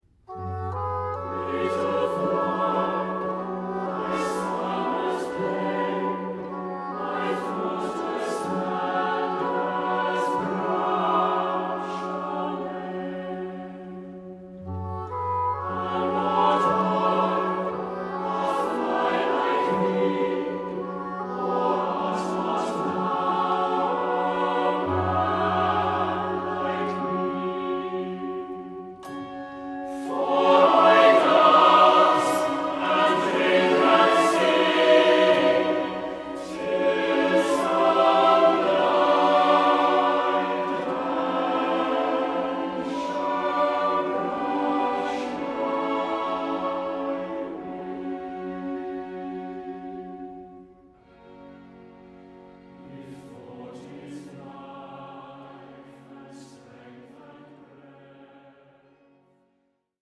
Choir & Baroque Orchestra